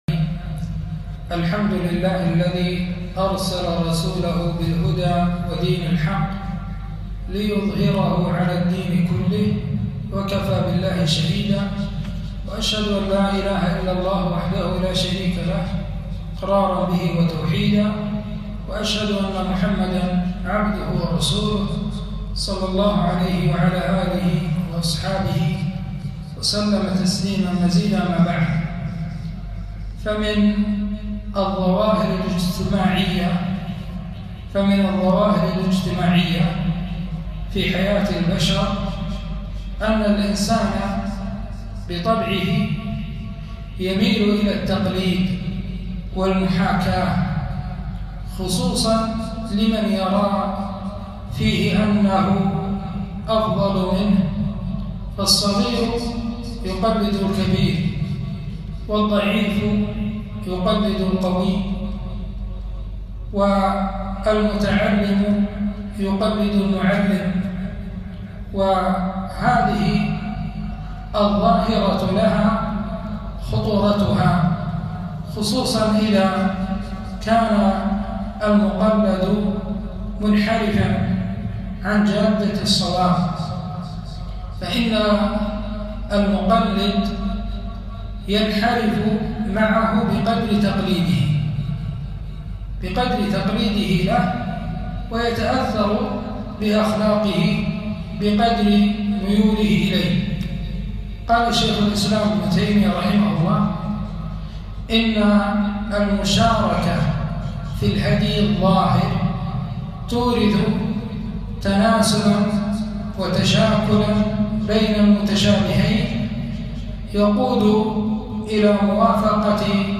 محاضرة - الأسوة الحسنة وخطر القدوة السيئة